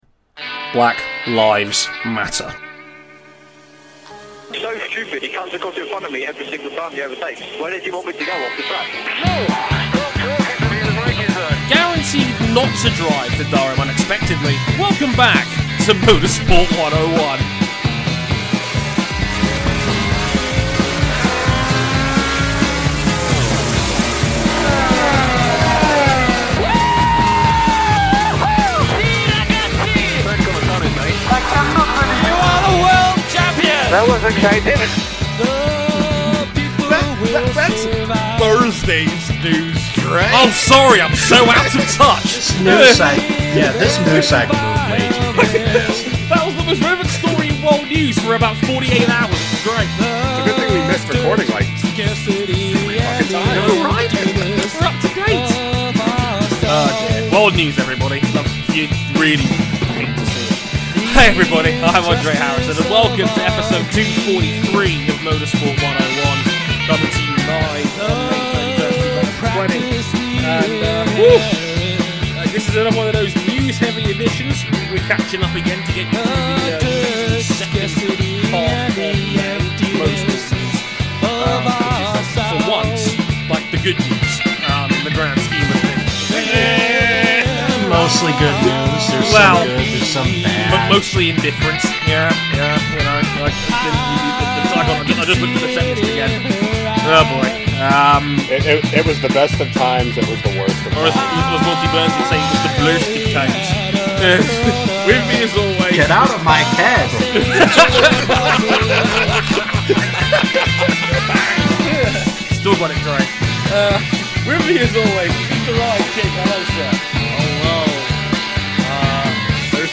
Note: There are 8 minutes and 46 seconds of empty space at the end of the episode.